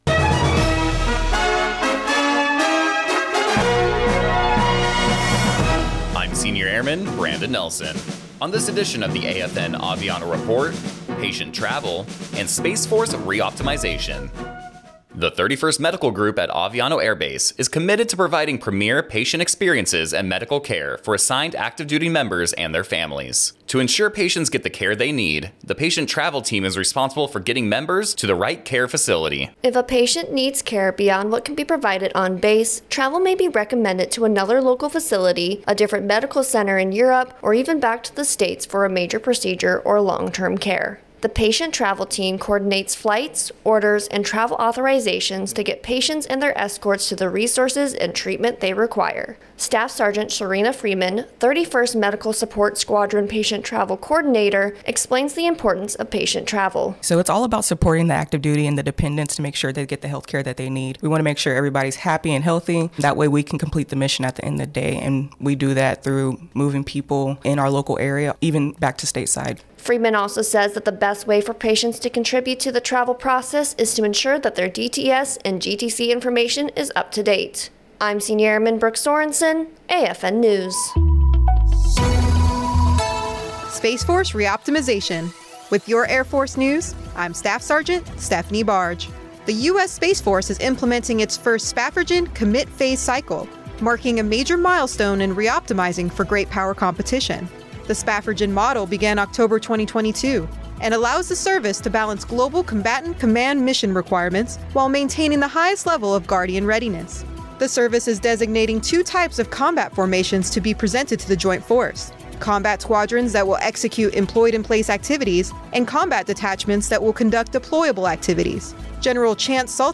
American Forces Network (AFN) Aviano radio news reports on the 31st Medical Group’s patient travel team and the work they do to ensure patients are receiving the care they need.